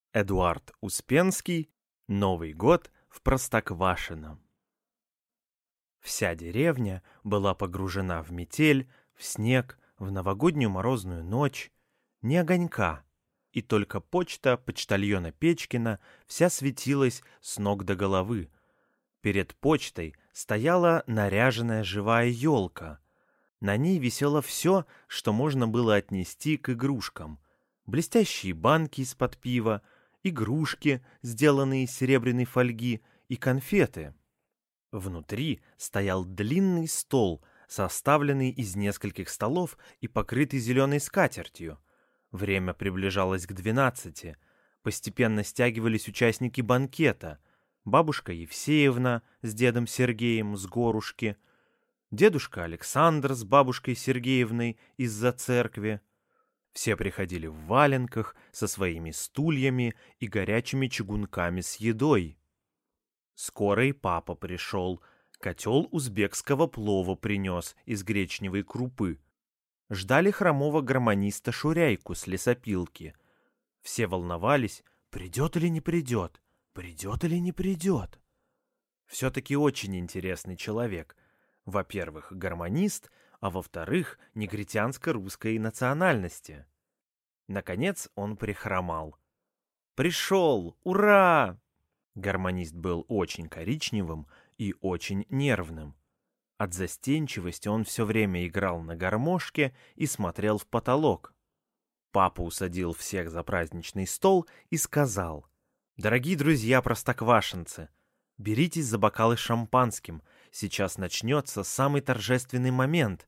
Аудиокнига Новый год в Простоквашино | Библиотека аудиокниг